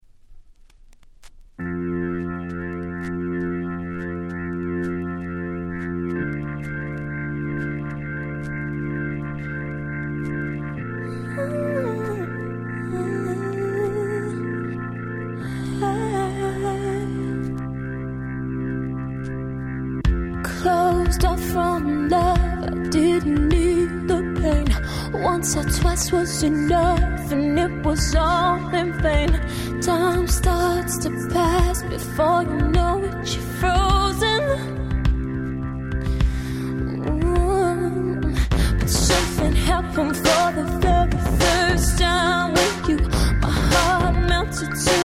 (試聴ファイルは別の盤から録音してございます。)
08' 世界的大ヒットR&B♪